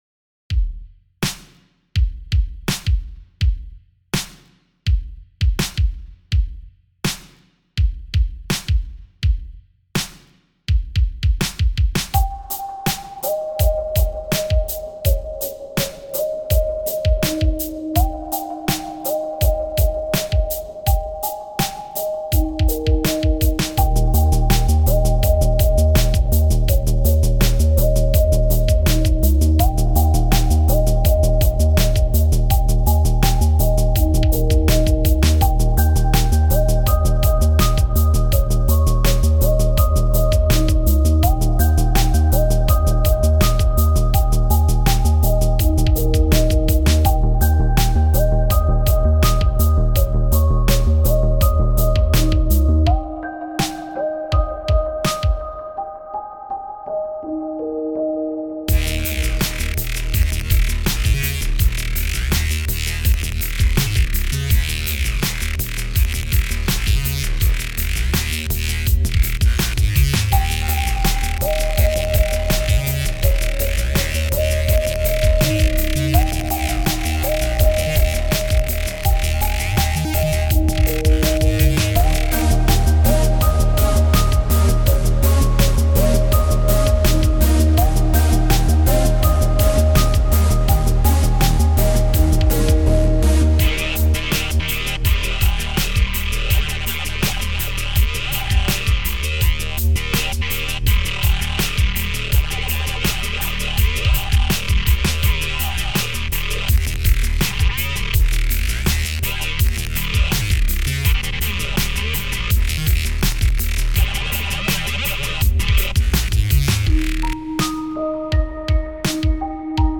It's got drums, it's got some sawteeth, a first attempt at some hyperactive bass to try and rip your head off, with a curious sounding toy piano.
Music / Techno
techno trance electronic
This was more of an experiment with different types of bass instruments.